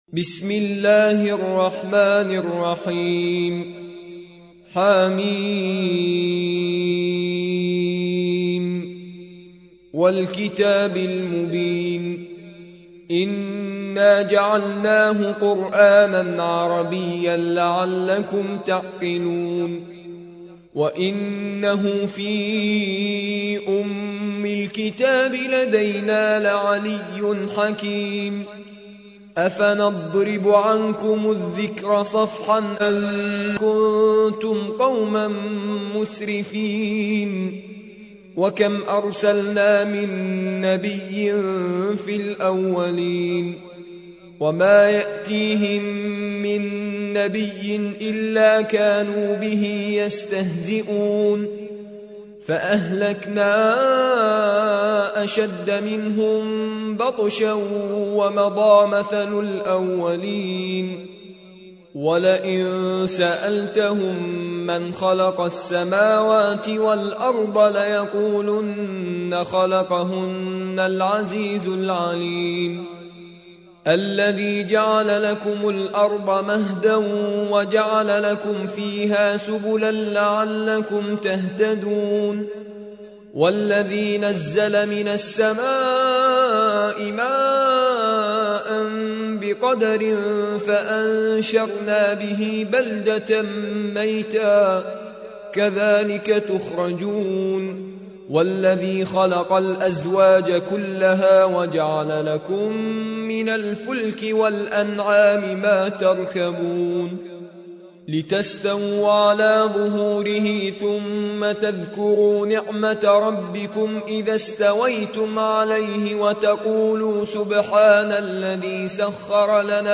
43. سورة الزخرف / القارئ